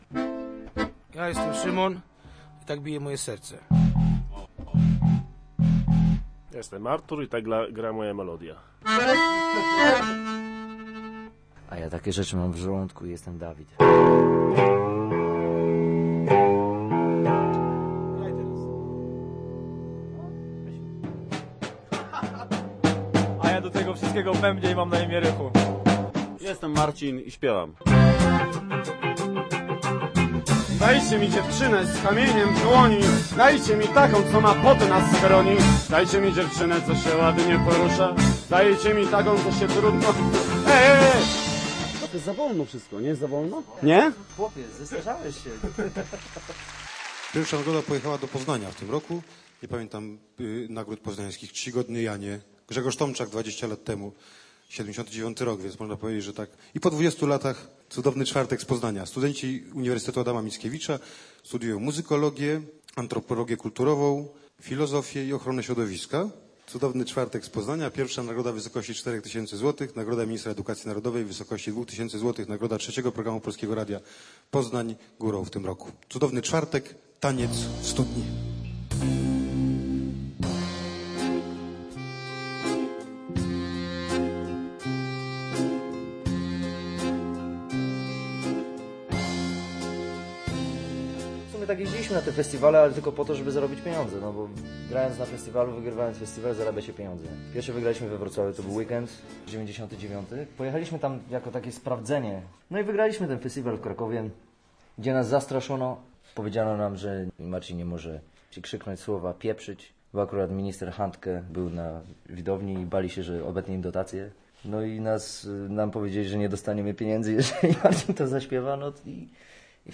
Zapraszamy do wysłuchania reportażu pt. Więcej niż Kraków o zespole "Cudowny Czwartek".